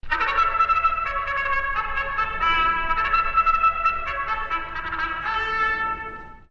Horse Race Call To Track, Bugle